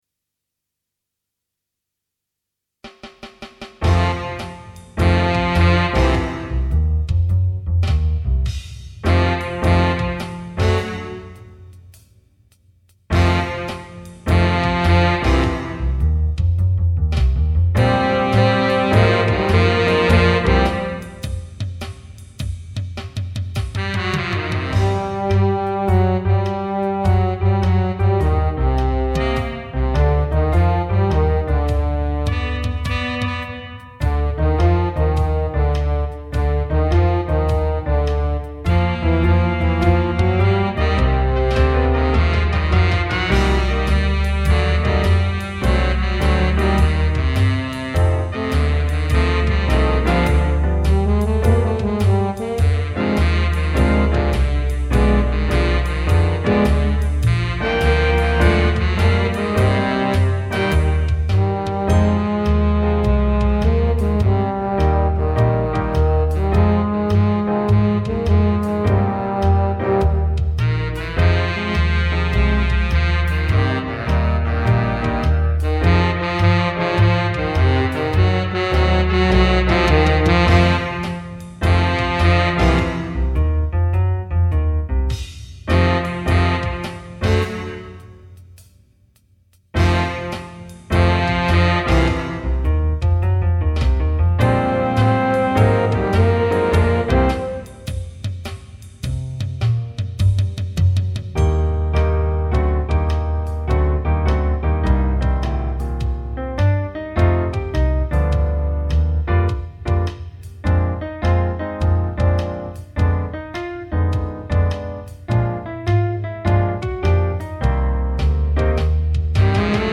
minus Instrument 5